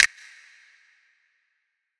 YM - Reverb Snap 2.wav